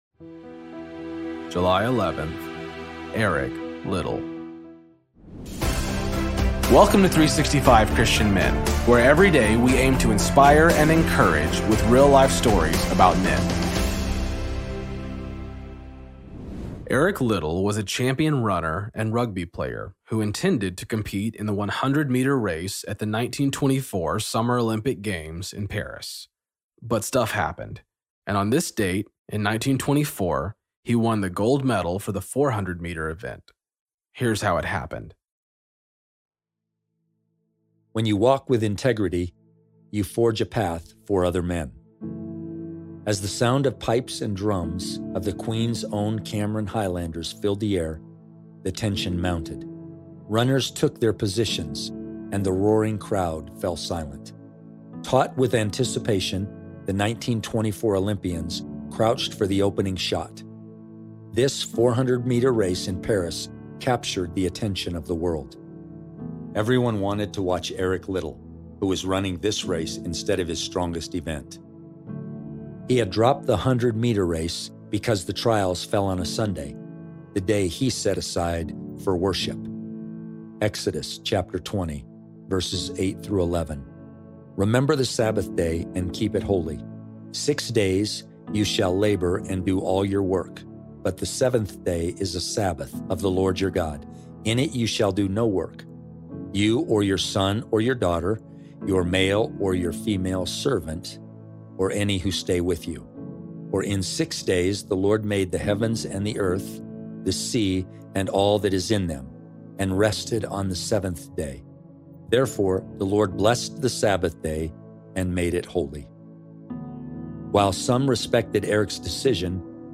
Story read by
Introduction read by